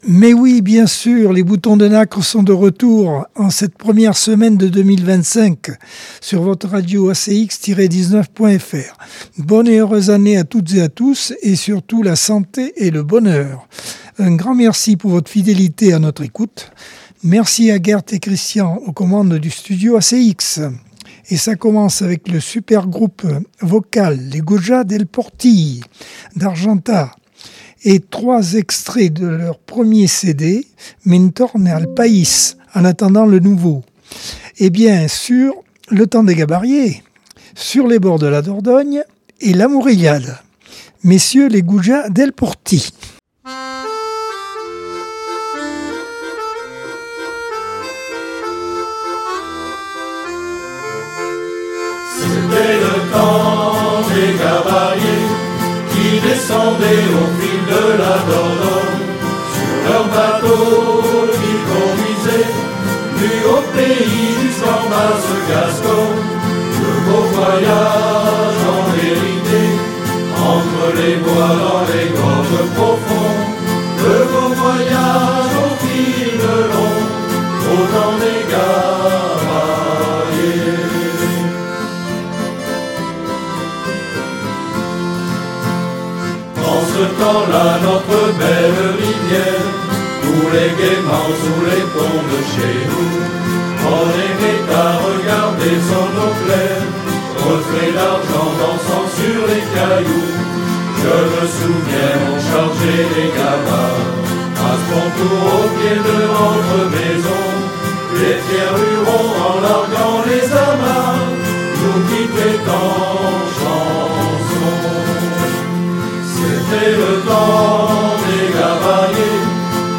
Accordeon 2025 sem 01 bloc 1 - Radio ACX